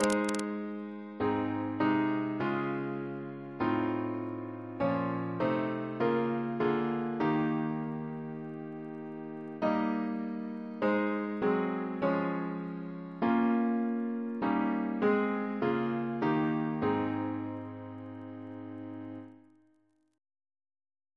Double chant in A♭ Composer: Sir George Elvey (1816-1893), Organist of St. George's Windsor; Stephen's brother Reference psalters: ACB: 8; ACP: 185; RSCM: 44